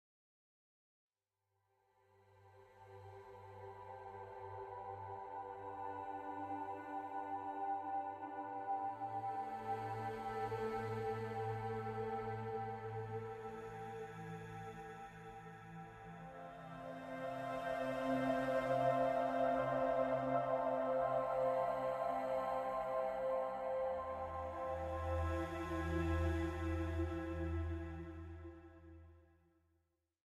🎧 396Hz Solfeggio frequency.